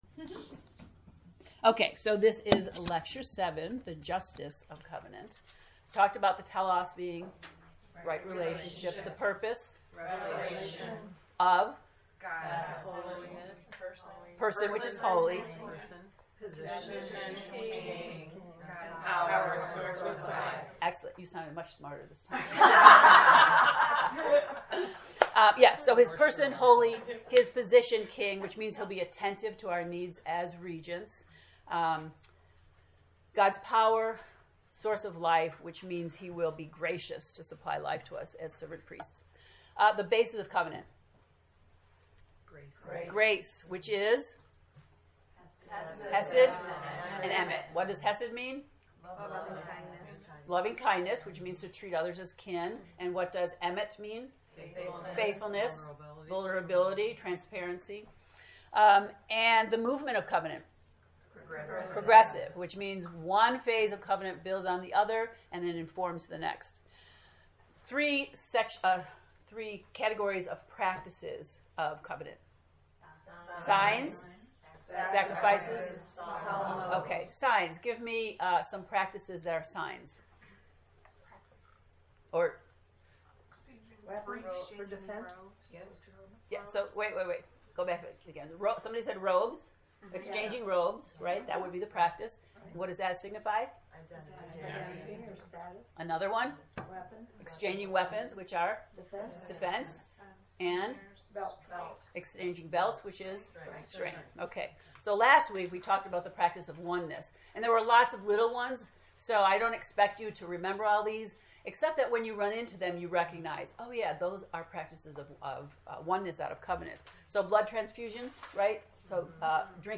COVENANT lecture 7
To listen to the lecture, “The Justice of Covenant,” click below: